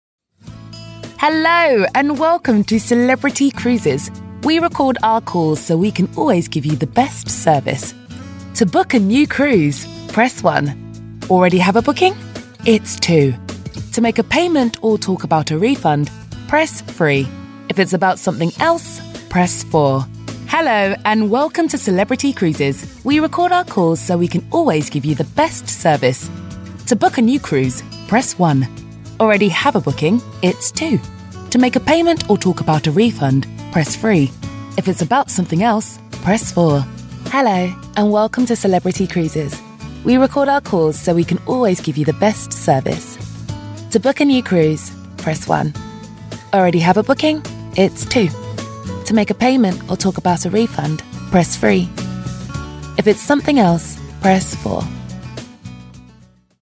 English (British)
Warm, Commercial, Deep, Friendly, Corporate
Explainer